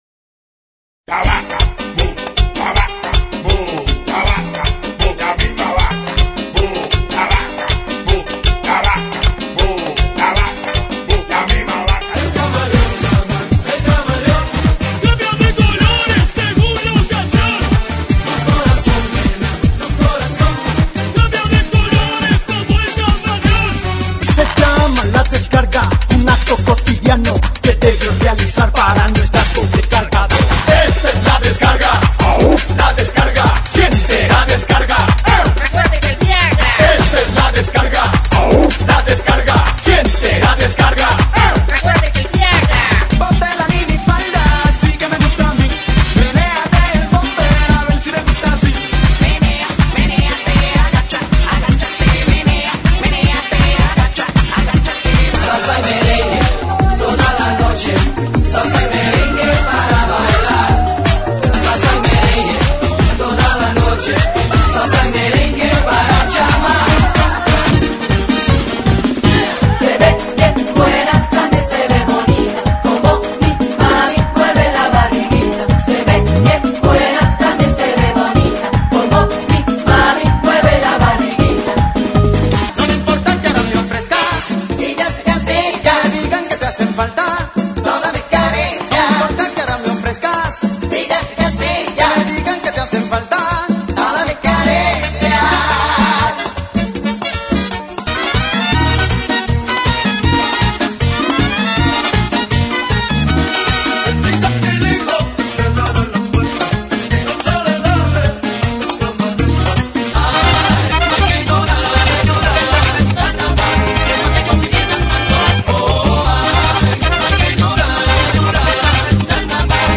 GENERO: MERENGUE – LATINO